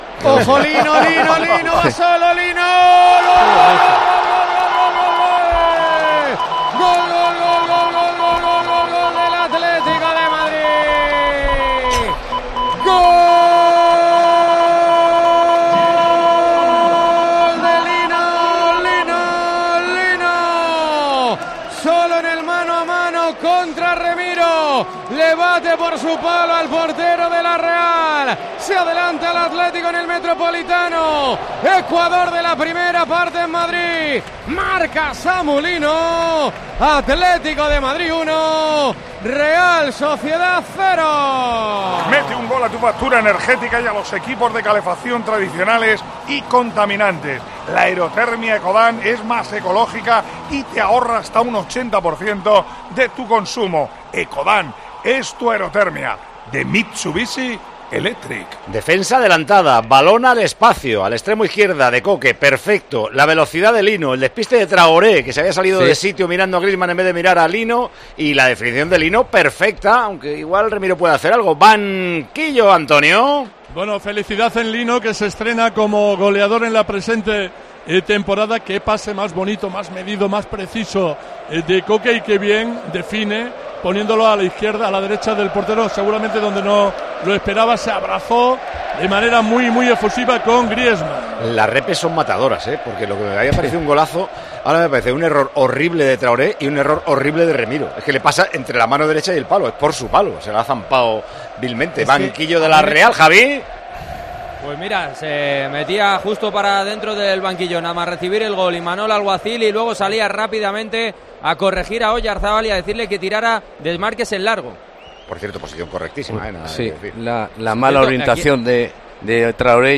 ASÍ SE VIVIÓ EN TIEMPO DE JUEGO LA VICTORIA DEL ATLÉTICO
Con Paco González, Manolo Lama y Juanma Castaño